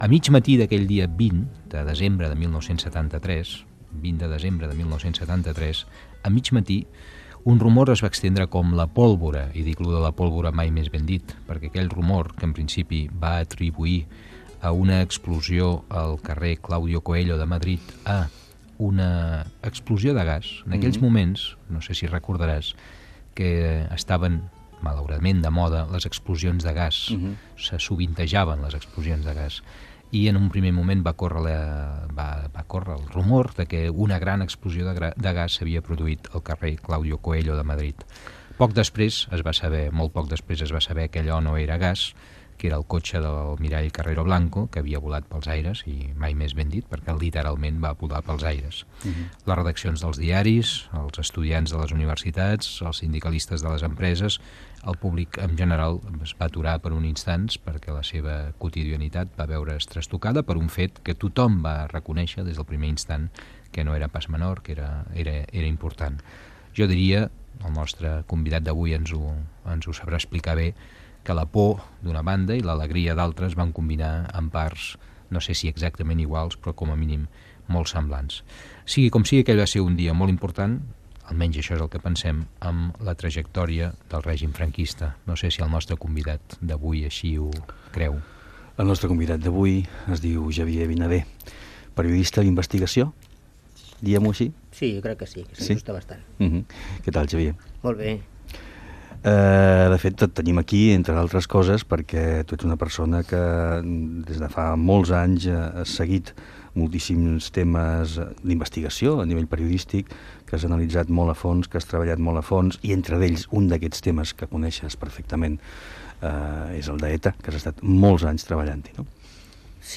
Fragment d'una entrevista
Divulgació